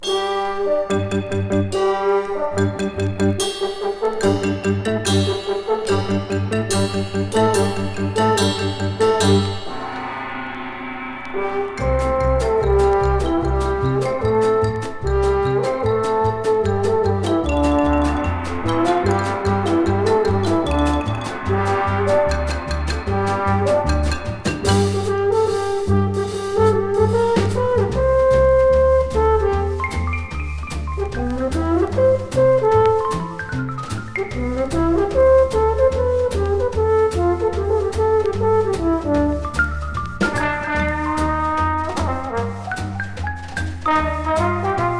Jazz　USA　12inchレコード　33rpm　Mono